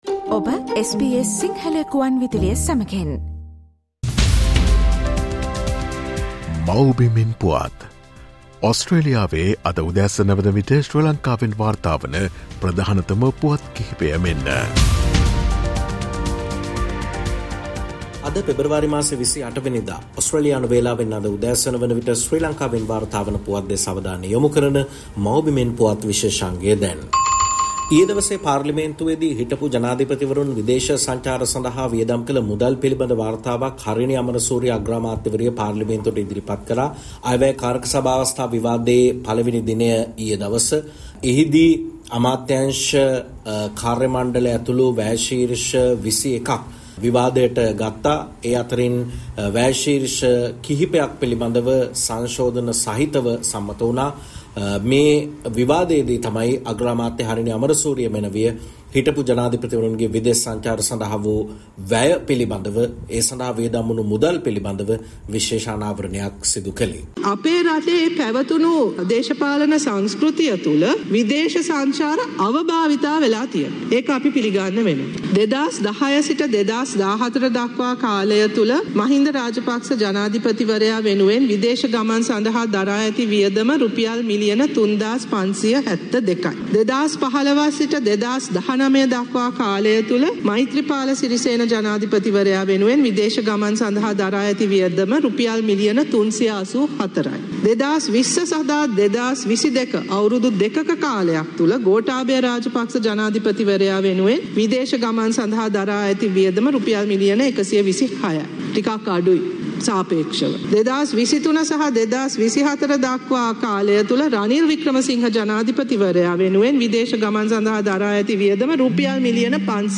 Prime Minister Harini reveals expenses incurred by former presidents on foreign trips: Homeland news 07:34 Harini Amarasuriya_ Prime Minister of Sri Lanka 2025.